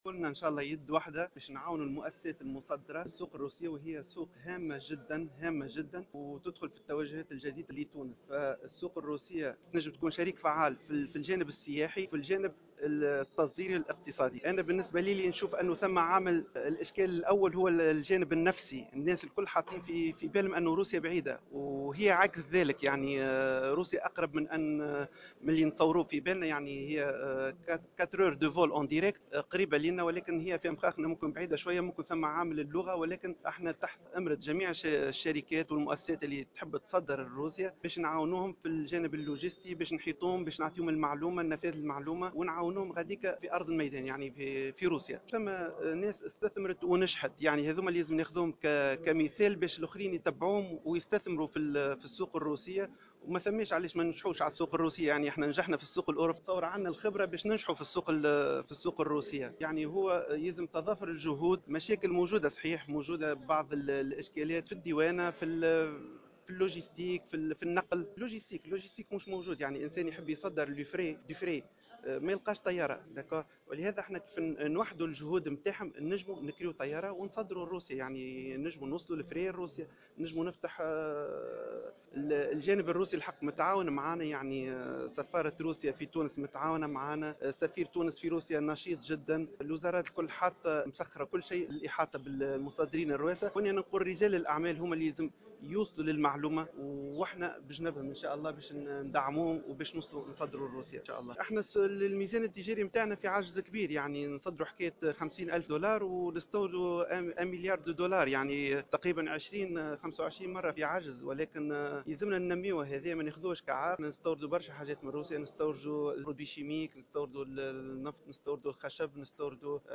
خلال ندوة اقتصادية